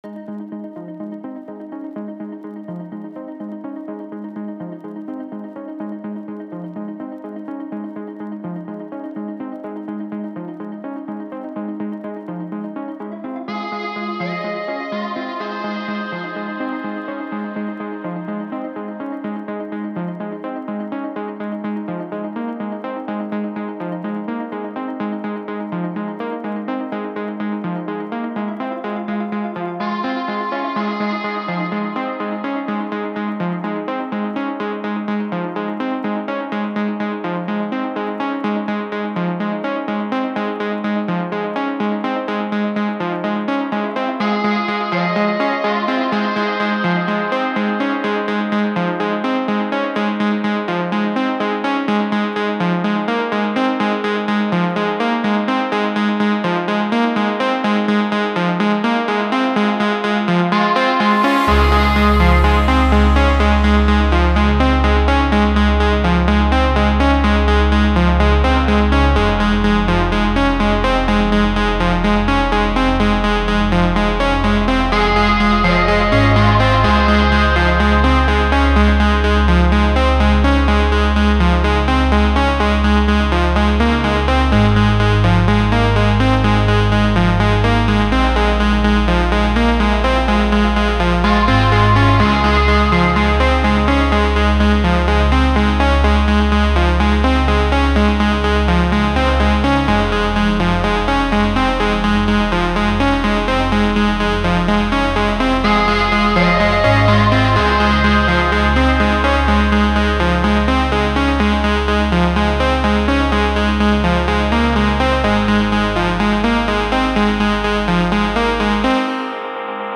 Назад в Melodic Progressive
Стиль: Progressive House